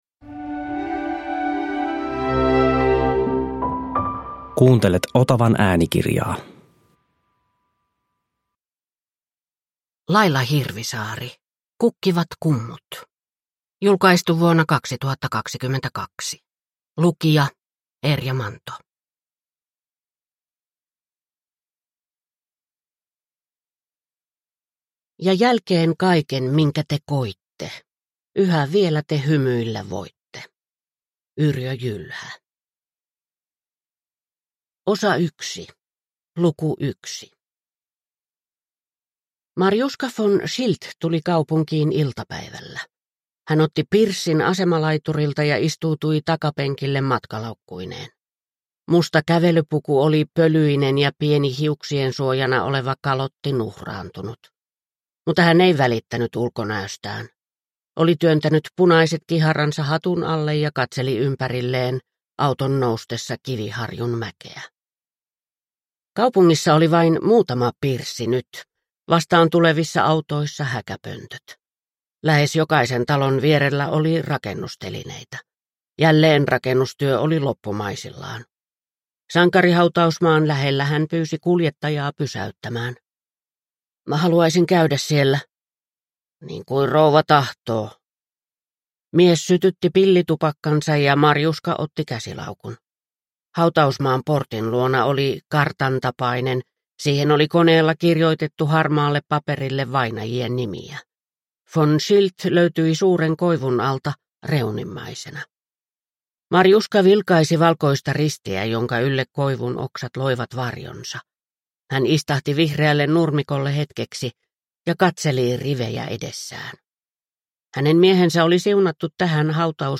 Kukkivat kummut – Ljudbok – Laddas ner